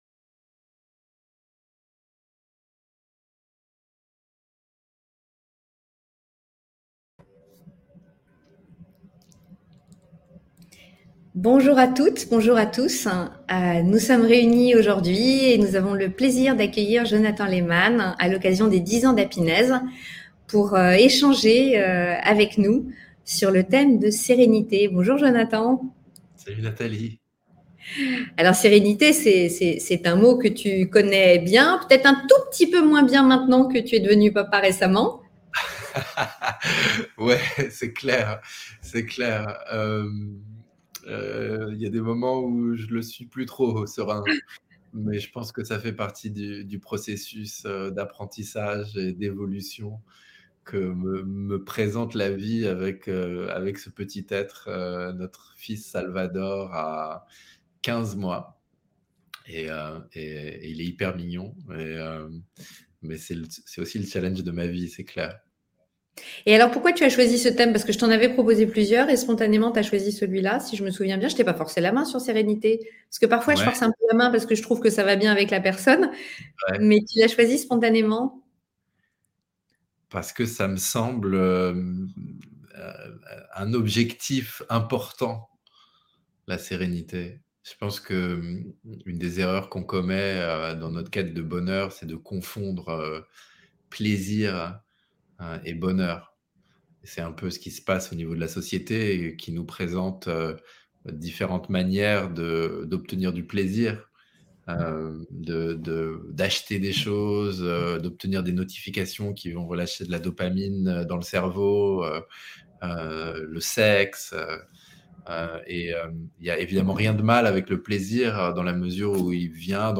Interview 10 ans - Sérénité